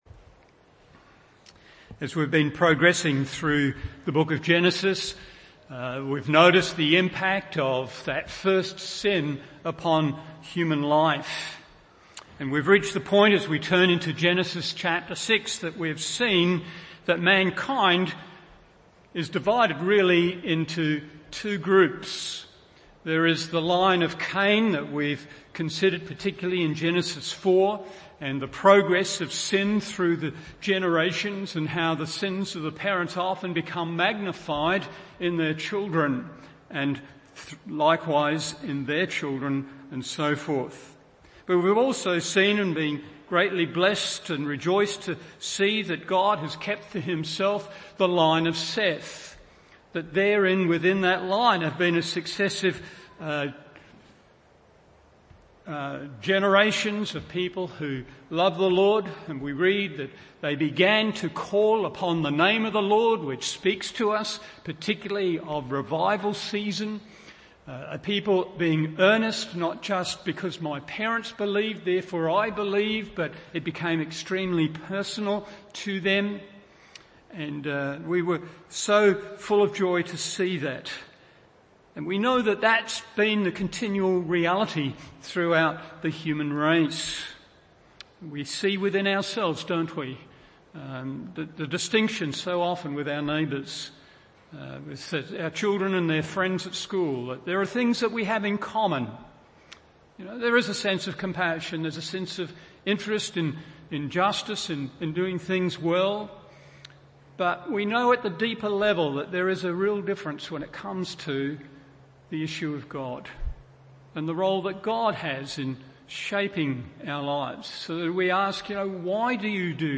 Morning Service Genesis 6:1-3 1.